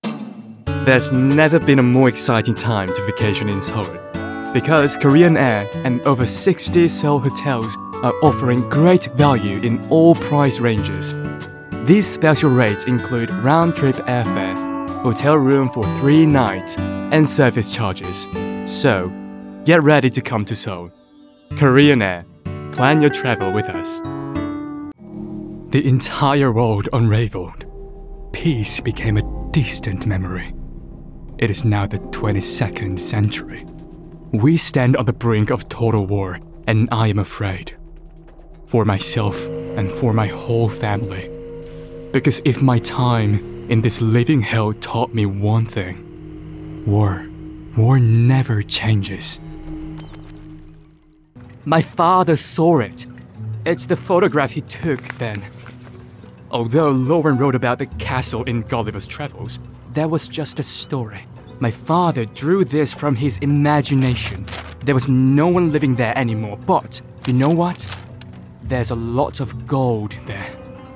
Korean, Male, 20s-30s